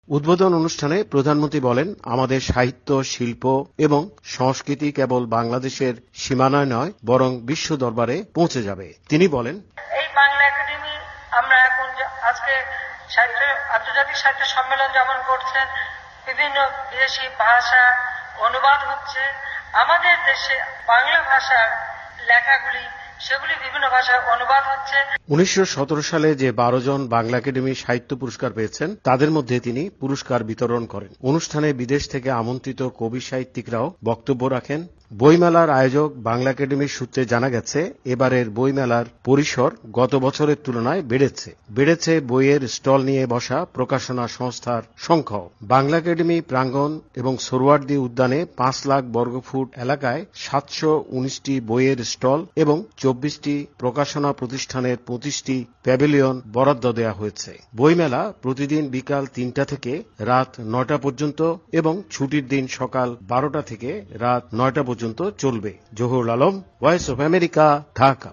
এ বিষয়ে ঢাকা থেকে বিস্তারিত জানিয়েছেন সংবাদদাতা